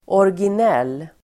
Uttal: [årgin'el:]